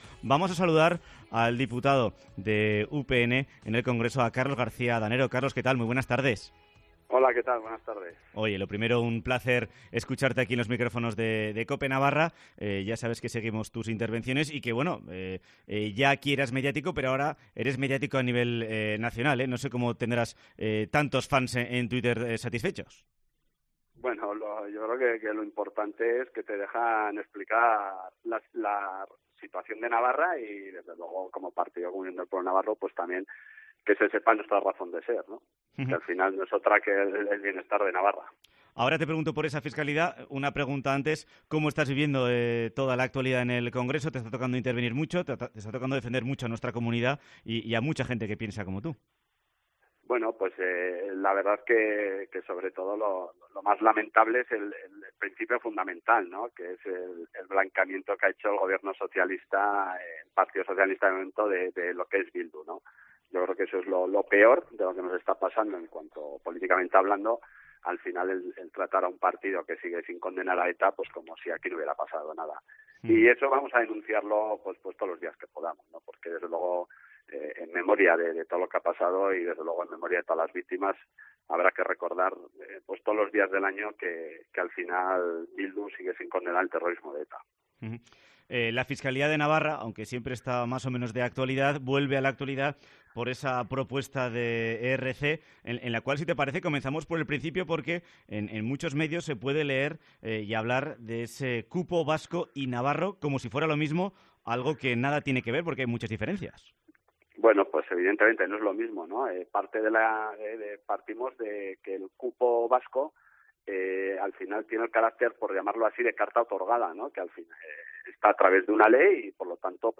Entrevista con Carlos García Adanero en COPE Navarra